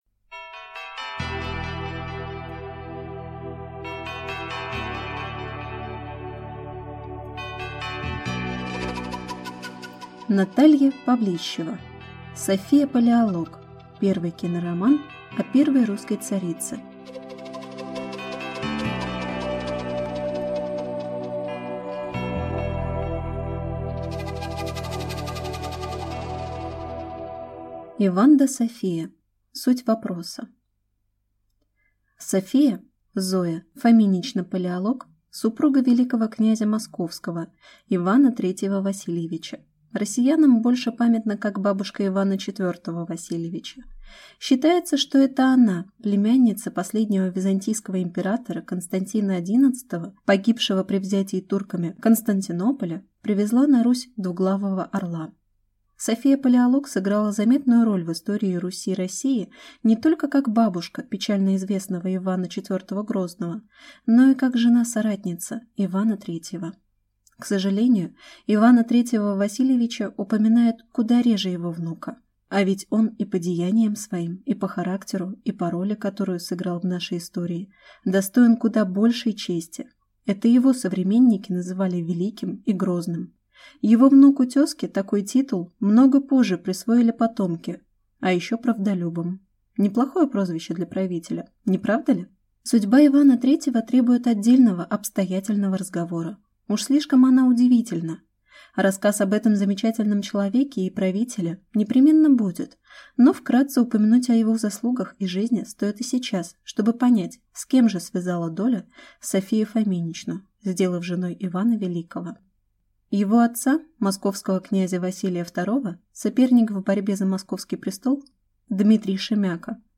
Аудиокнига София Палеолог. Первый кинороман о первой русской царице | Библиотека аудиокниг